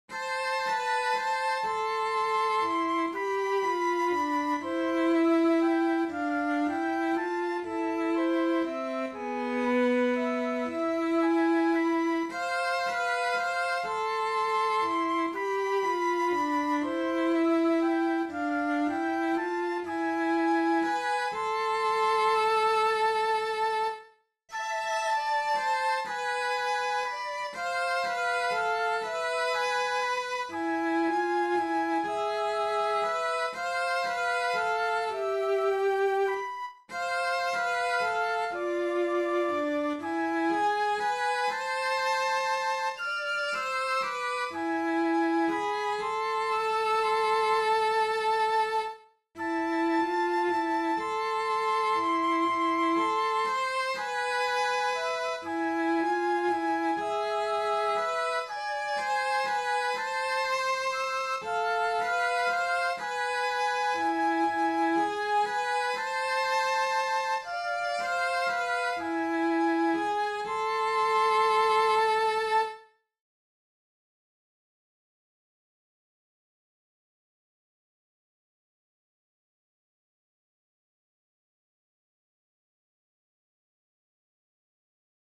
Hiljainen-vuoro-sello-ja-huilut.mp3